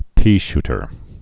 (pēshtər)